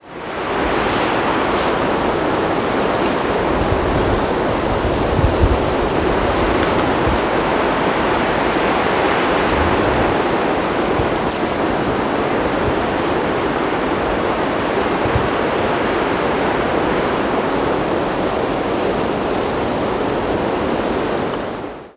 Golden-crowned Kinglet Regulus satrapa
Flight call description A high, finely trilled "seee" or a series of two to four "see" notes all on one pitch.
"See" calls from perched bird.